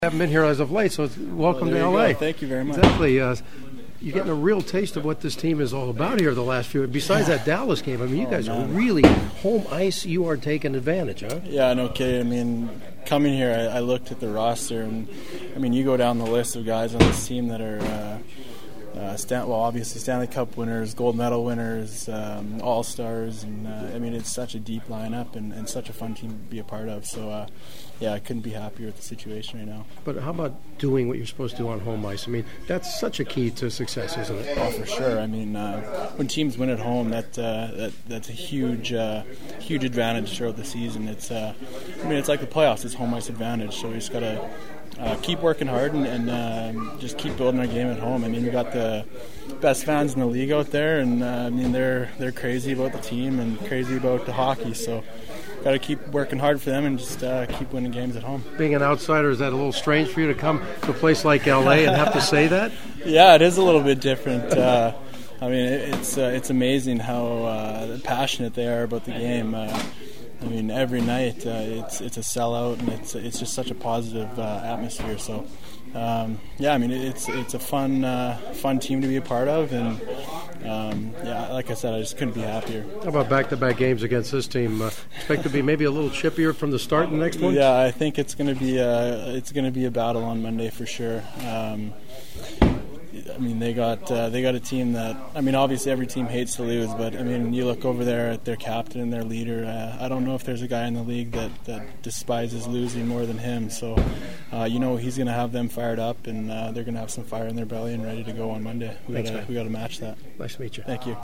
Kings defenseman Keaton Ellerby finally meeting him for the first time: